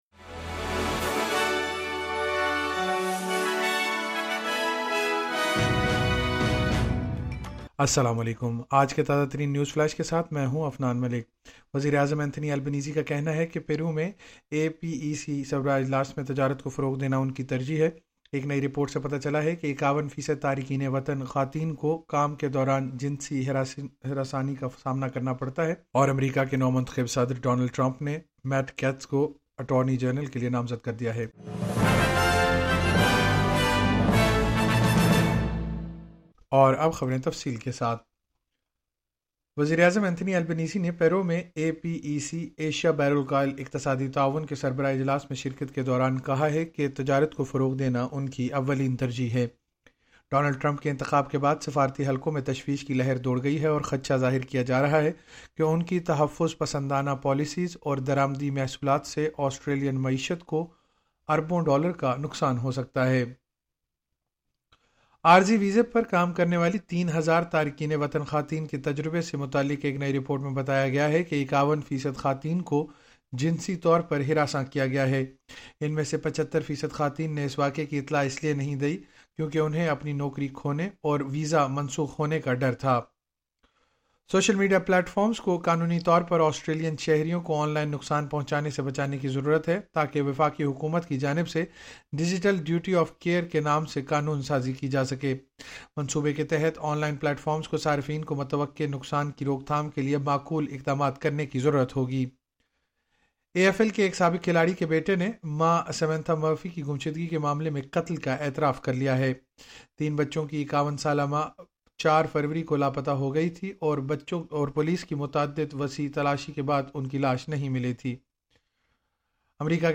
نیوز فلیش: 14 نومبر 2024 کی مختصر خبریں